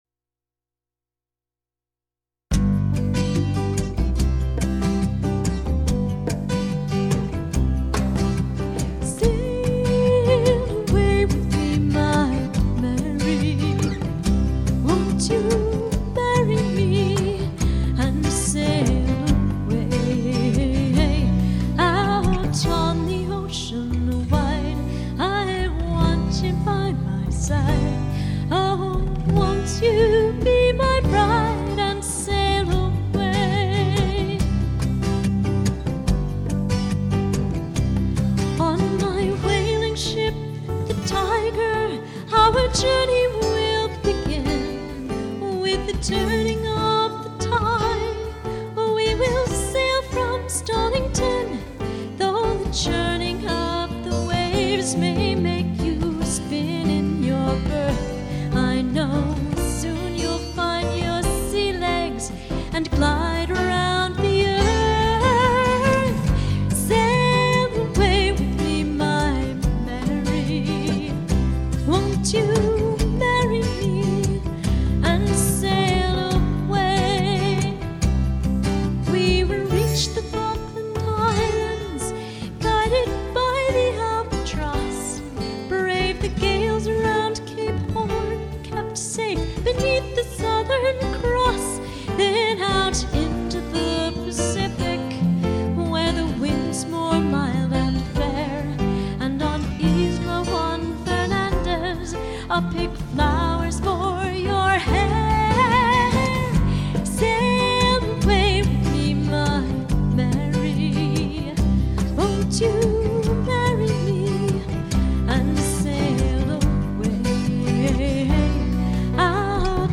STYLE:  Piano Propelled FOLK/POP,
Contemporary and Neo-traditional Folk